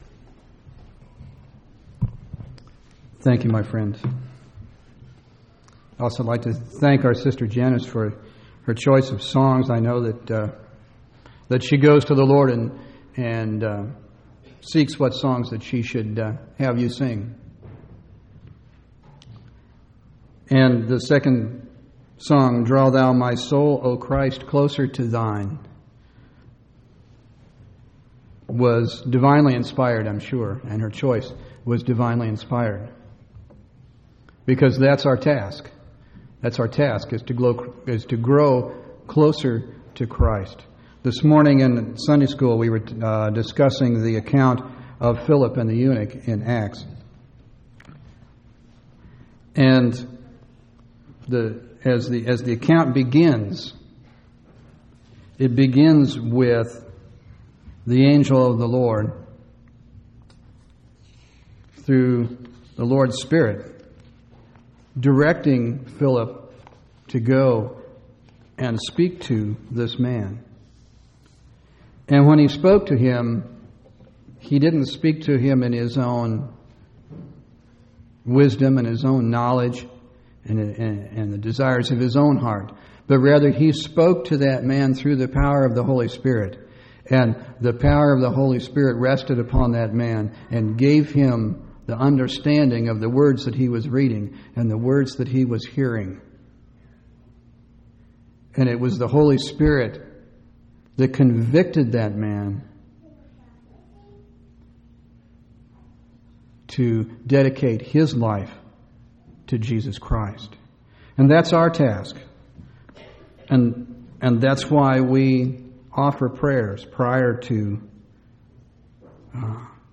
5/26/2002 Location: Temple Lot Local Event
audio-sermons